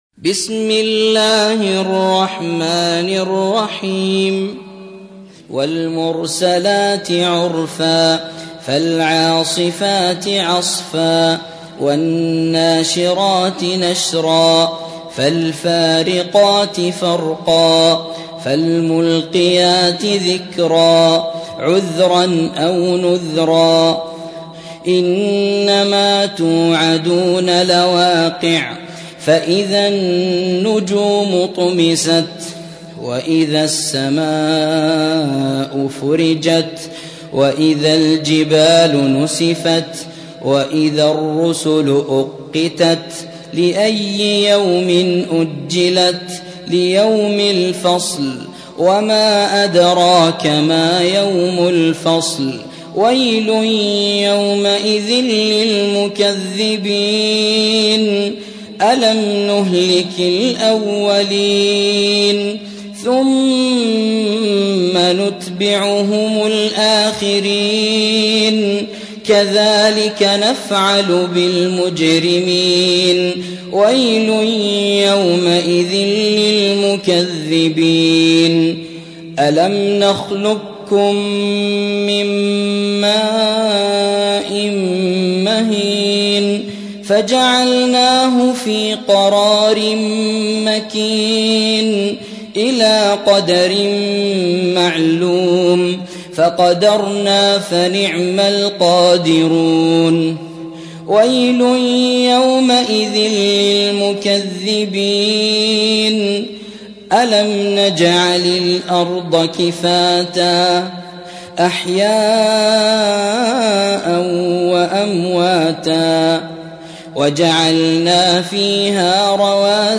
سورة المرسلات / القارئ